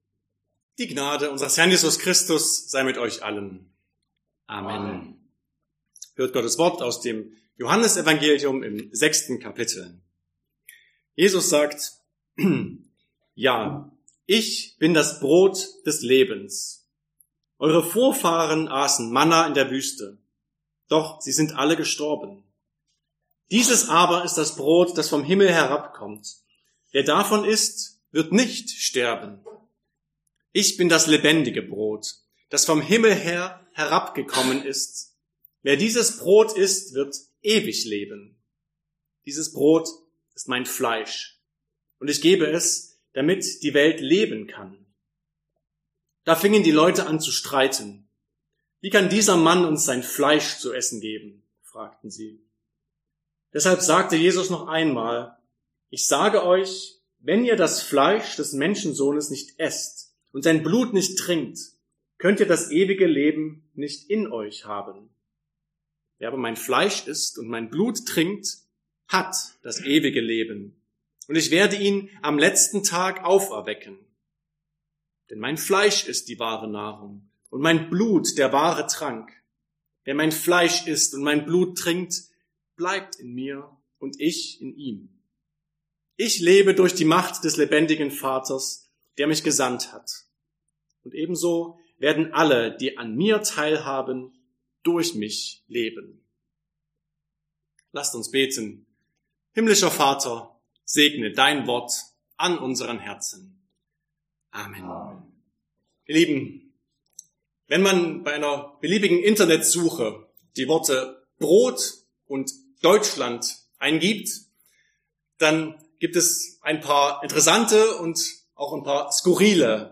Sonntag nach Trinitatis Passage: Johannes 6, 48-58 Verkündigungsart: Predigt « 8.